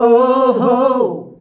OOOHOO  C.wav